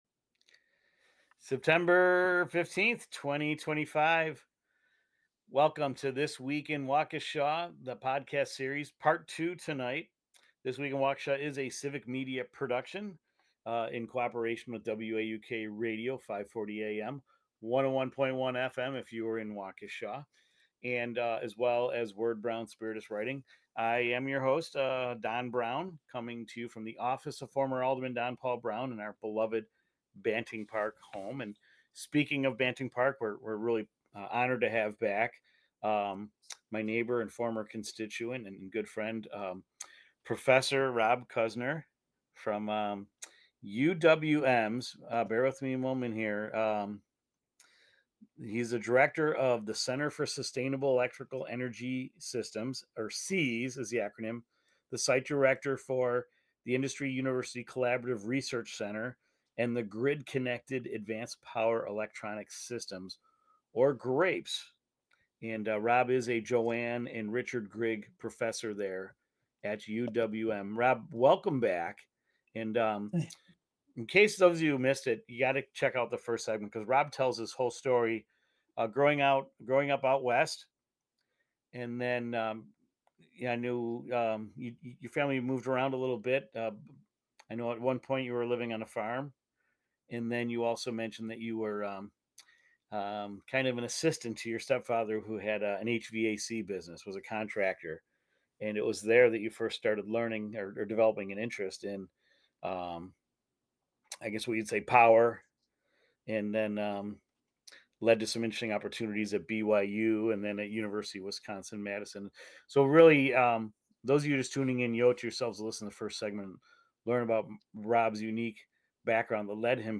He shares his journey from engineering in Wisconsin’s manufacturing hubs to earning his PhD later in life, and how his family, career, and community have shaped his path. Tune in for a conversation that blends innovation, perseverance, and Waukesha roots.